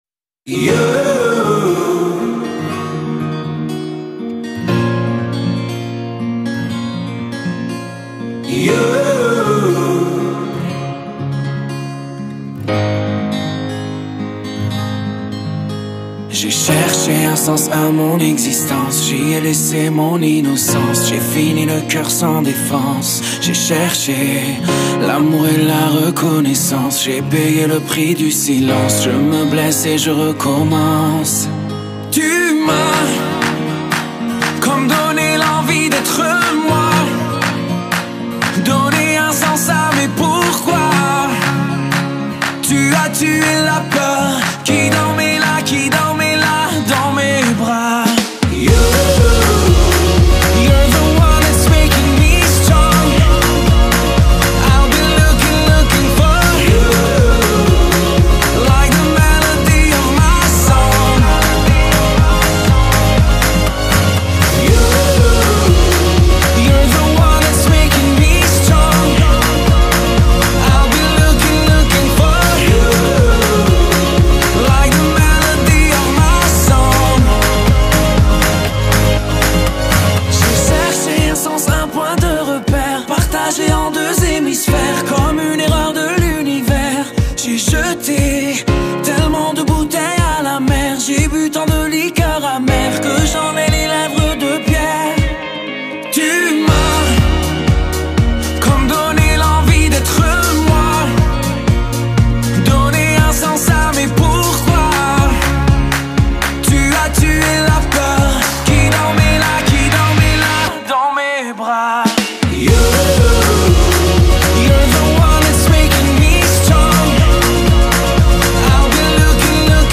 Категория: Поп Музыка